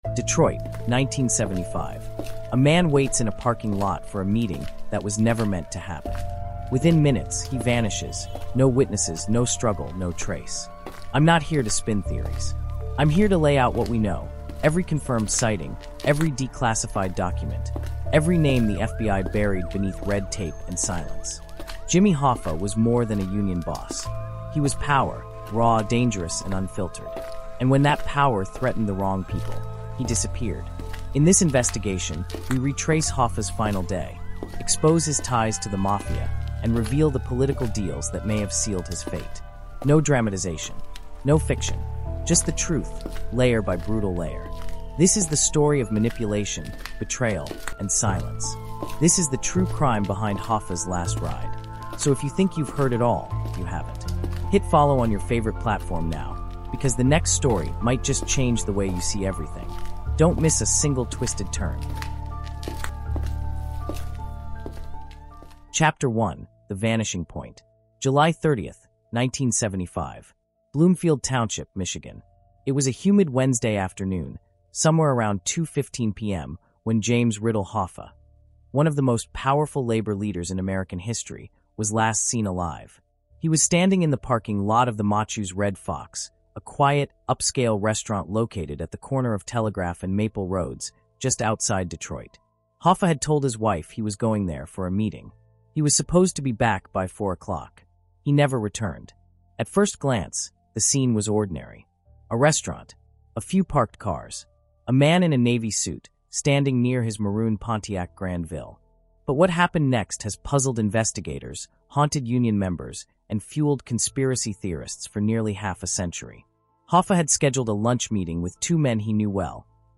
This gripping investigation delves into one of America's greatest unsolved disappearances, revealing how manipulation and dark psychology intertwine with labor power and political corruption. Narrated from the compelling viewpoint of an investigative journalist, the episode traces Hoffa's final moments in 1975, exposing decades of secrets surrounding mafia affiliations, government cover-ups, and the relentless pursuit of truth.